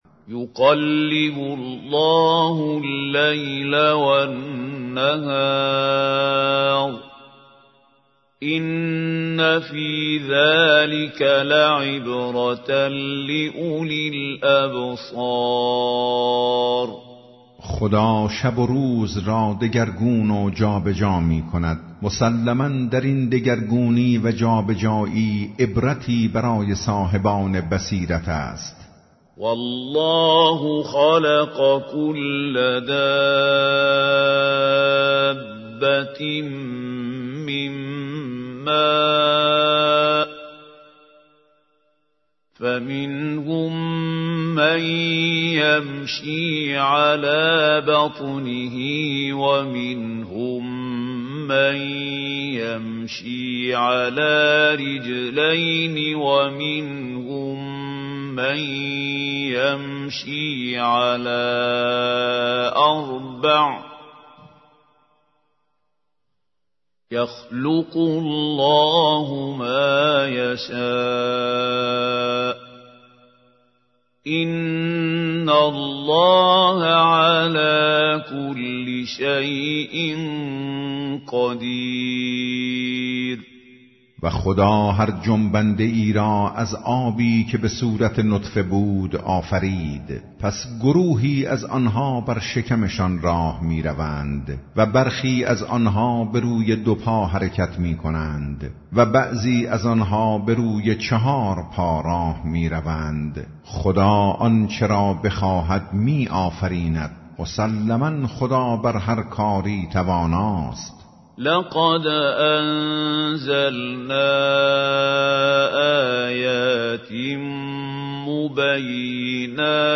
ترجمه گویای قرآن کریم - جزء ۱۸